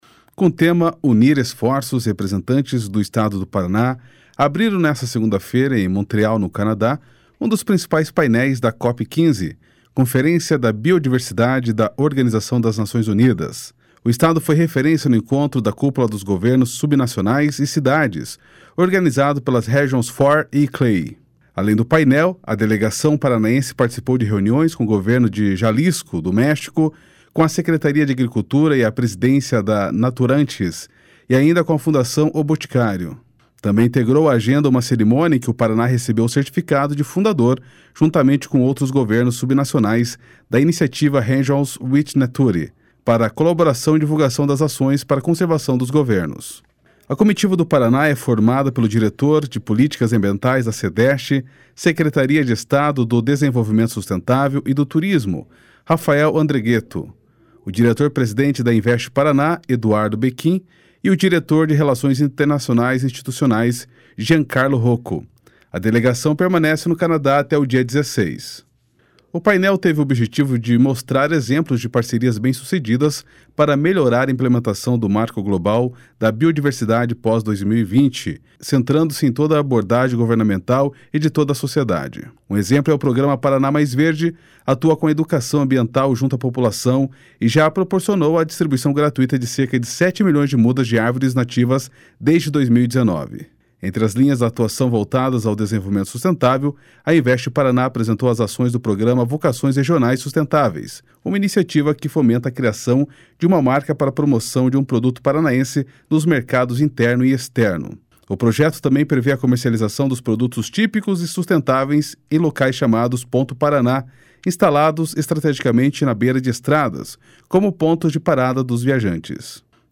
Da experiência paranaense apresentada na COP15, surgiu uma possível parceria com o México para ampliação da capacidade técnica entre as partes. (Repórter